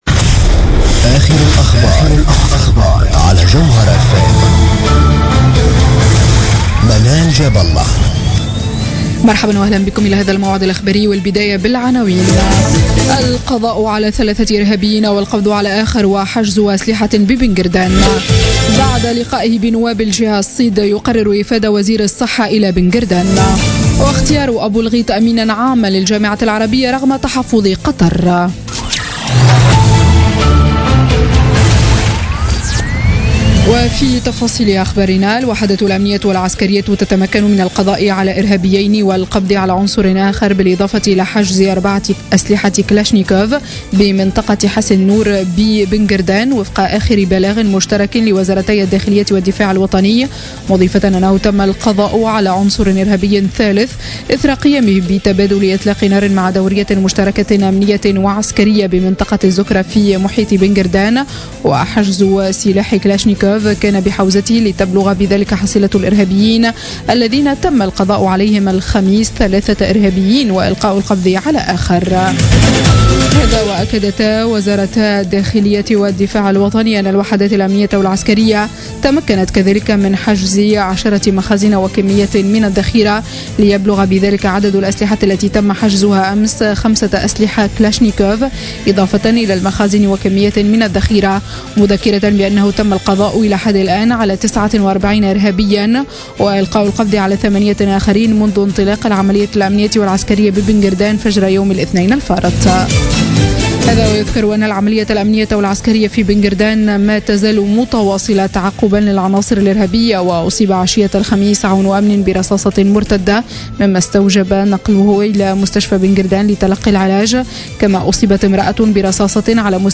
نشرة أخبار منتصف الليل ليوم الجمعة 11 مارس 2016